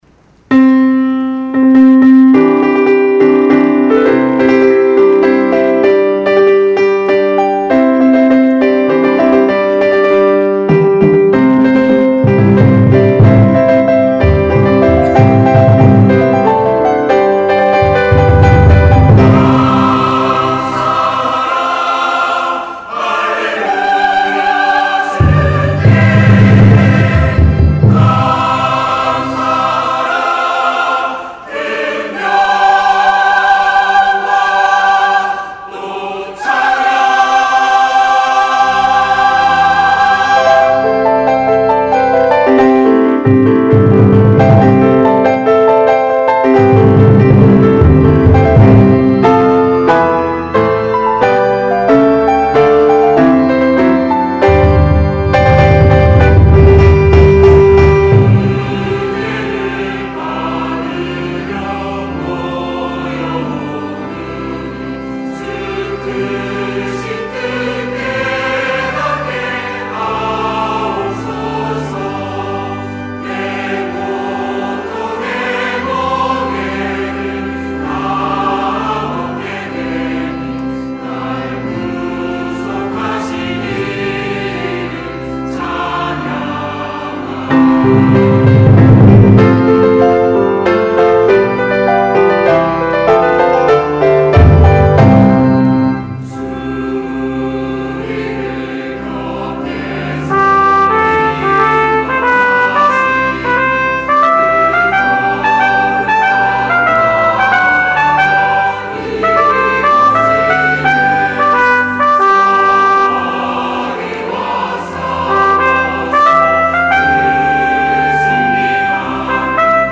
찬양 :: 140706 감사의 축제 찬송
감사의 축제 찬송- 시온 찬양대 -